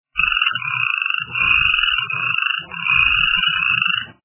Жабы - Скрекочут Звук Звуки Жаби - скрекочуть
» Звуки » Природа животные » Жабы - Скрекочут
При прослушивании Жабы - Скрекочут качество понижено и присутствуют гудки.